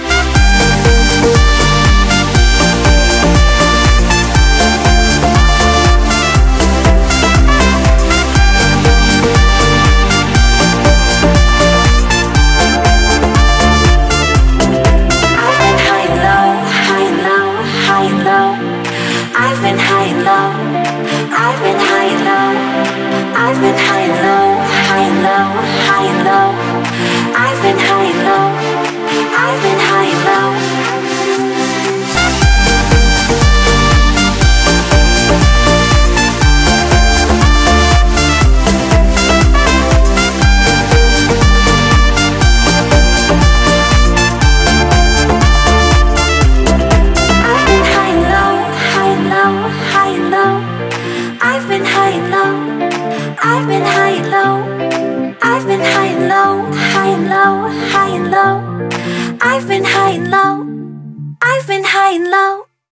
• Качество: 320, Stereo
ритмичные
женский вокал
dance
club
house
труба